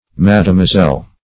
mademoiselle - definition of mademoiselle - synonyms, pronunciation, spelling from Free Dictionary
Mademoiselle \Ma`de*moi`selle"\, n.; pl.